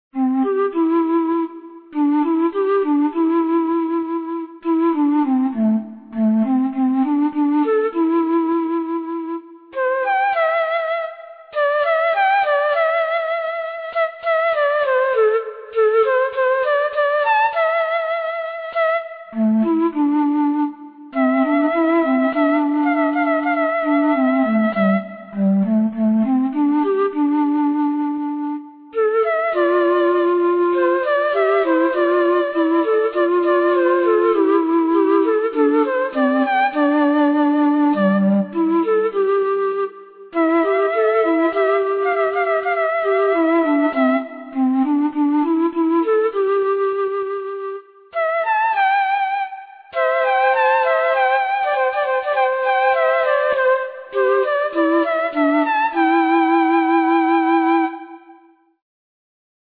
Cette courte pièce, composée en 2025, utilise une gamme pentatonique. Après l'avoir initialement composée pour deux flûtes (flûte et flûte alto), en ut majeur, Jean-Pierre Vial en a publié une transposition plus idiomatique, pour les touches noires du piano, en fa dièse majeur. La gamme pentatonique évoque les musiques asiatiques, et en particulier celles du Pays du Soleil Levant.